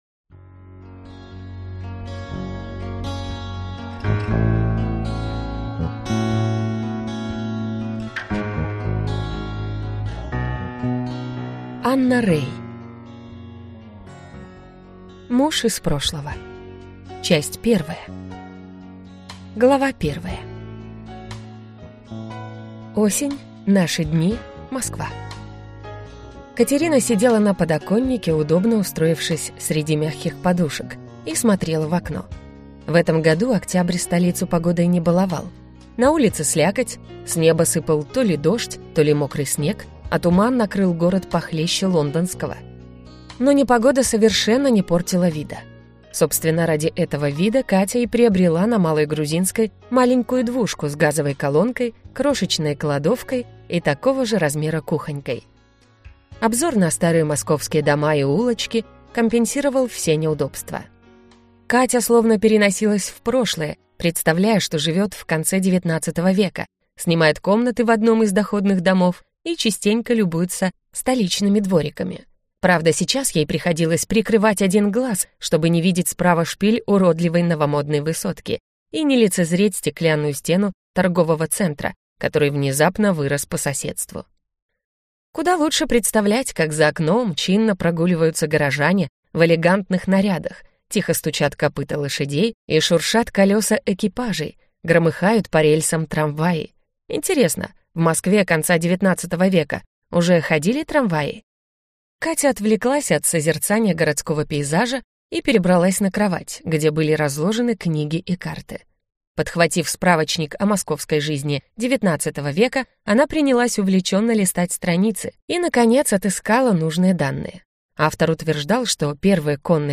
Аудиокнига Муж из прошлого | Библиотека аудиокниг